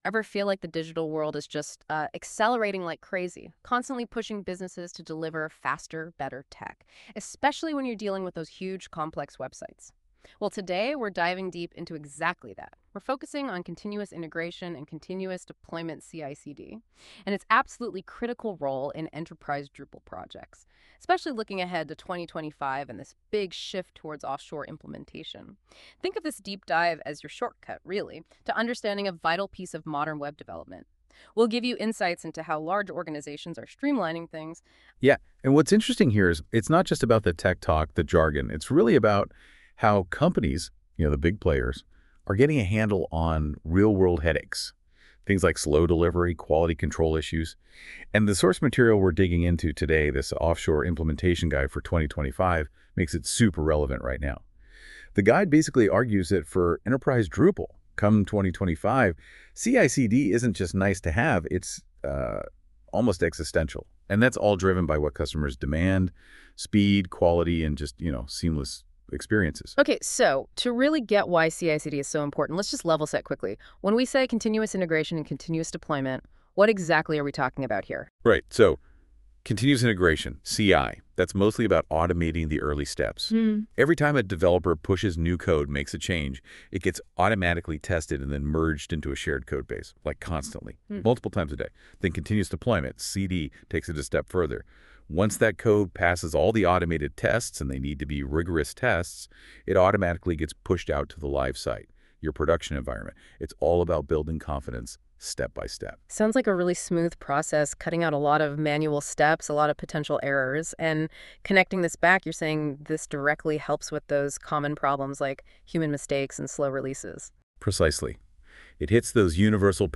AI Podcast